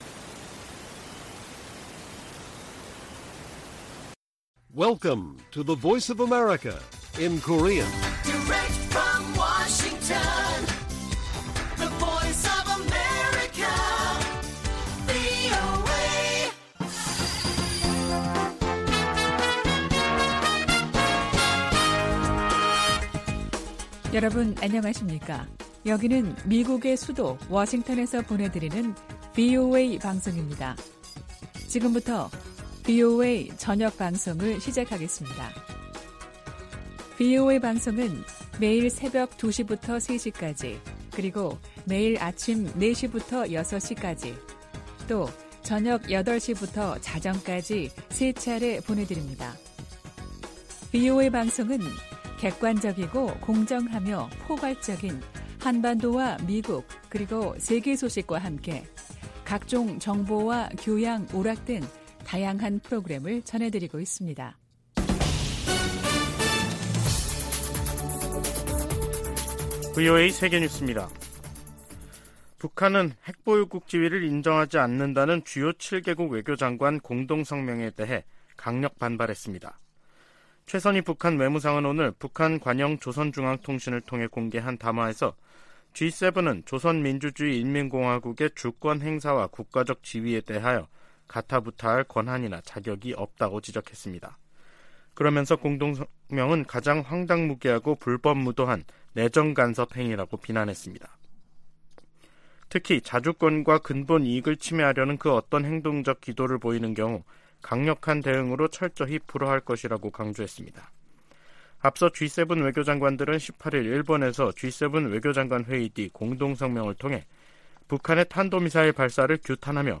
VOA 한국어 간판 뉴스 프로그램 '뉴스 투데이', 2023년 4월 21일 1부 방송입니다. 백악관은 윤석열 한국 대통령의 국빈 방문 기간 중 북한·중국 문제가 논의될 것이라고 밝혔습니다.